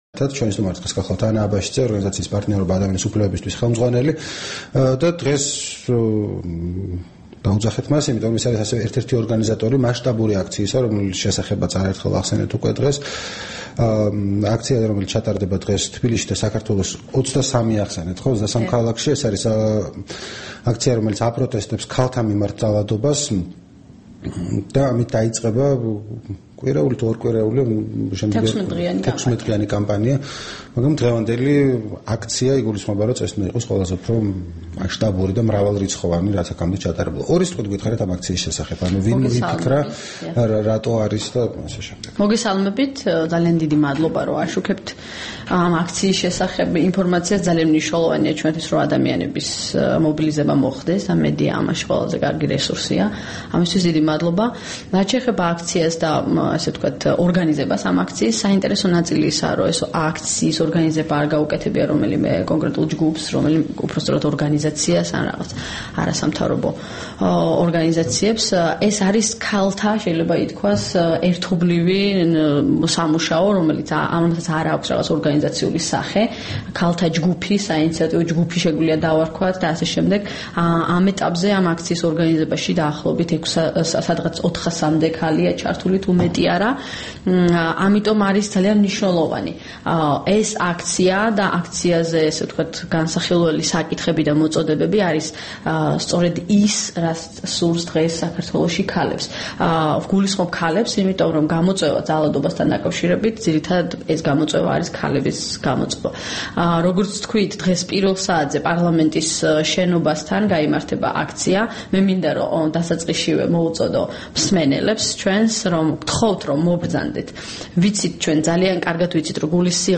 რადიო თავისუფლების თბილისის სტუდიაში სტუმრად იყო